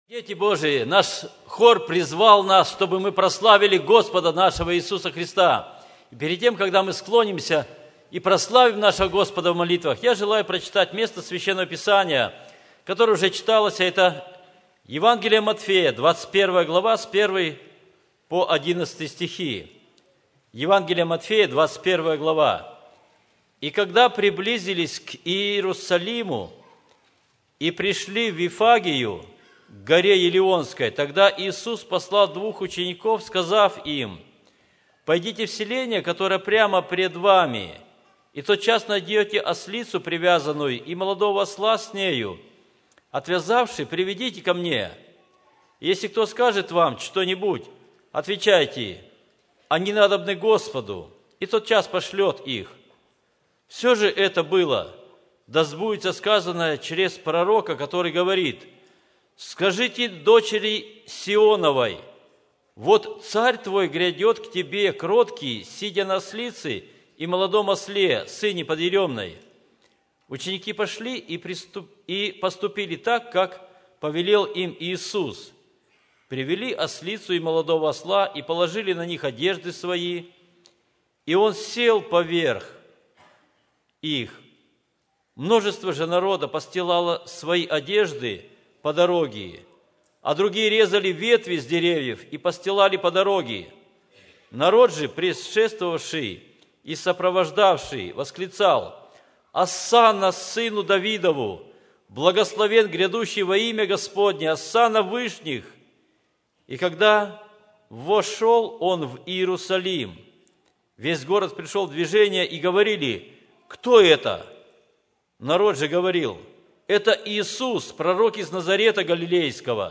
Все Проповеди